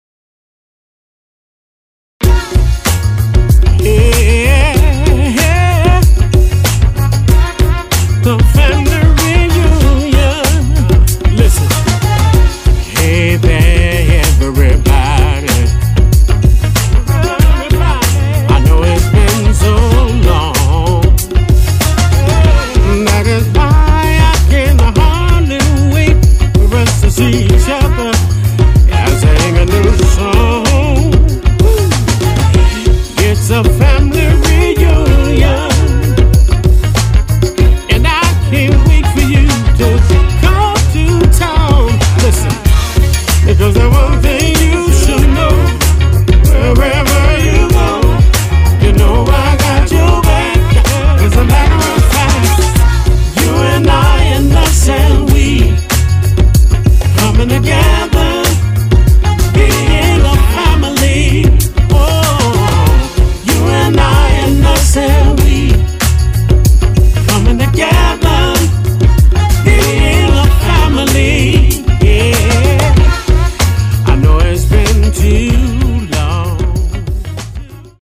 INSPIRATIONAL R&B